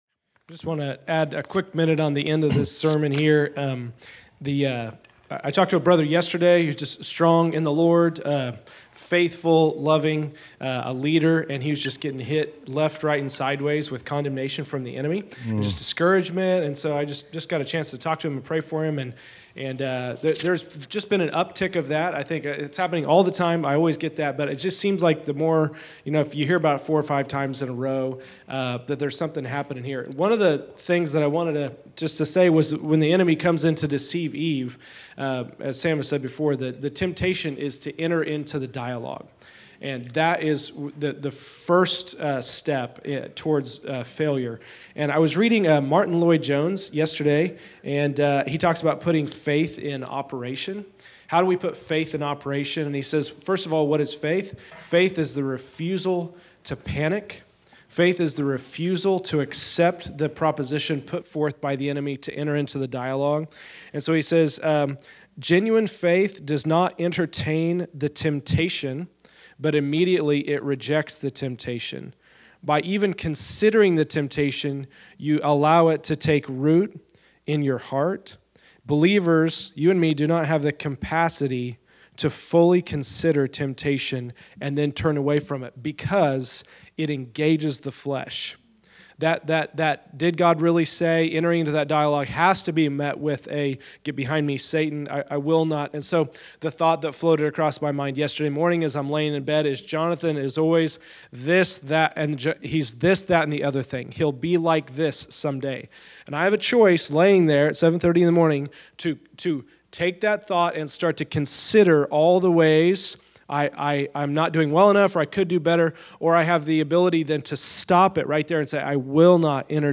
Category: Exhortation